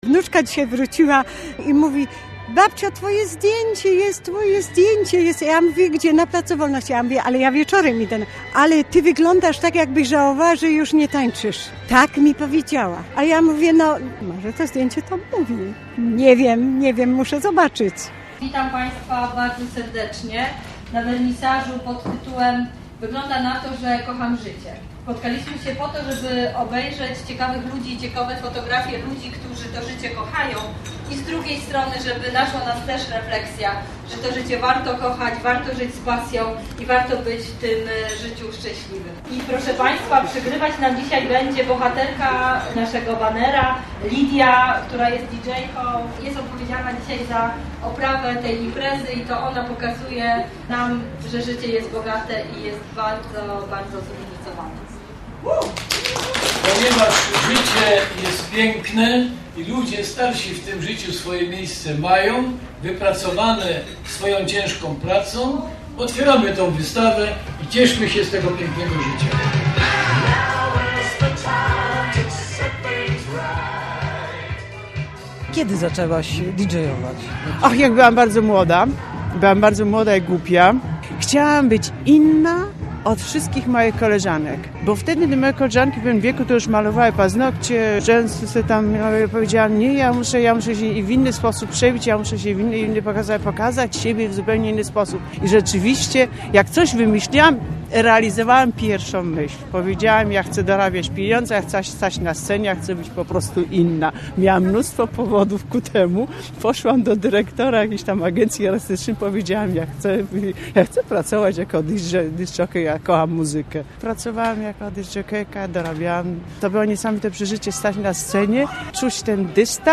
"Wygląda na to..." - reportaż